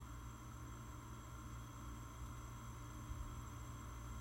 今回は防音設備が整った音楽スタジオで、アロマディフューザー３機種と加湿器の音量を実際に計測・録音しました。
FUWARI20B37.2db34.7db
※iphone15で各機器から50cm離して録音しました。
音声だけ聞くと、FUWARI10BとFUWARI20Bでは大きな差はありません。